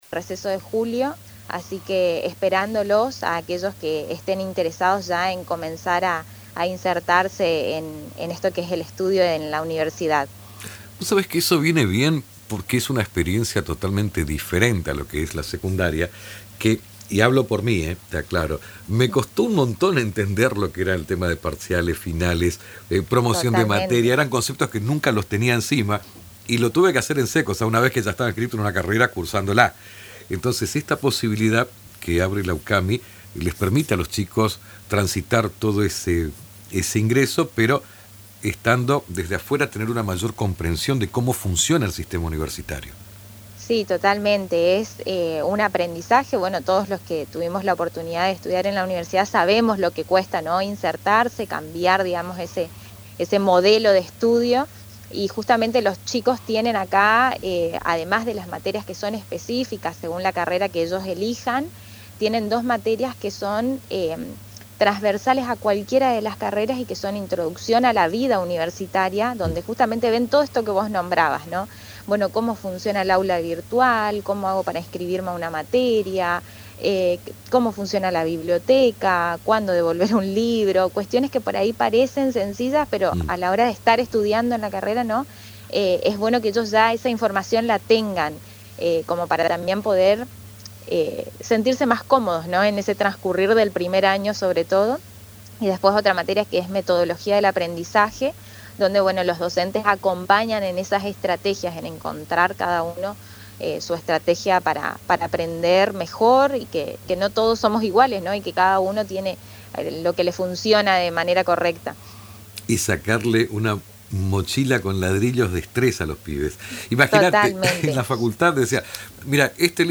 Aquí, la entrevista completa en Radio Tupambaé (FM 105.9)